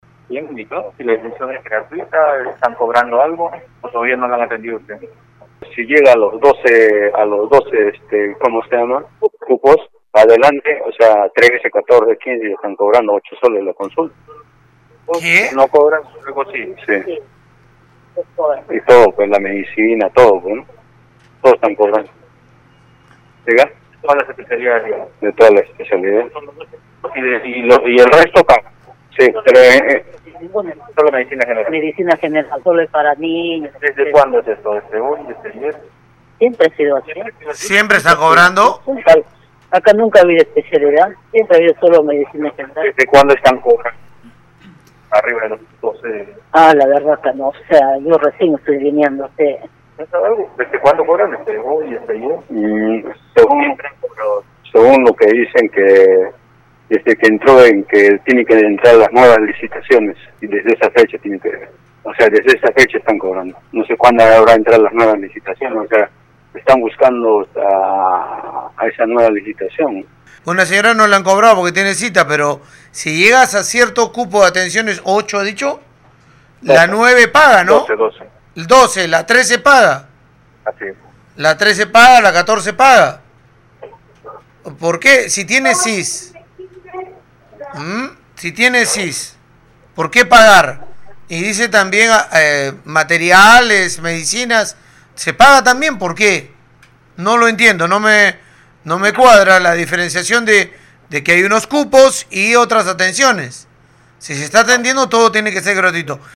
Desde el CLAS centro de salud Viñani, en el distrito Gregorio Albarracín, pacientes en espera confirmaron a Radio Uno cobro por atención, mismo reclamo que horas antes fue dado a conocer por ciudadanía vía esta emisora.